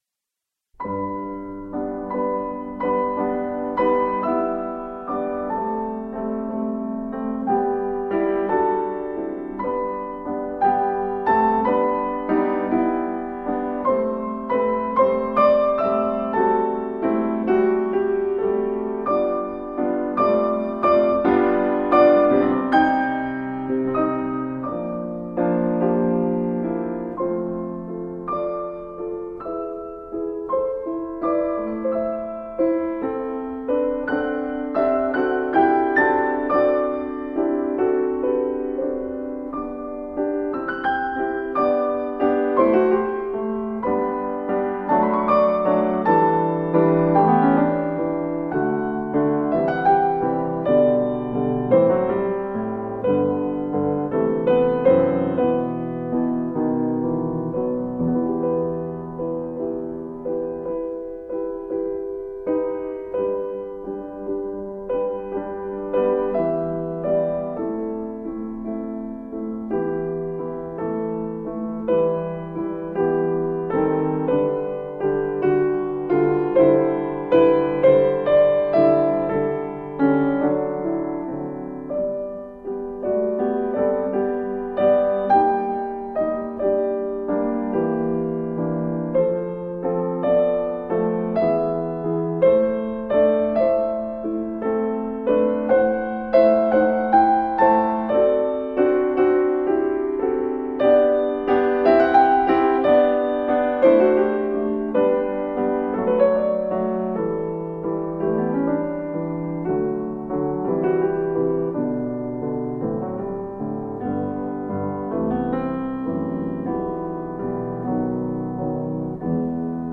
Classical works from a world renowned pianist.
intimate, graceful little works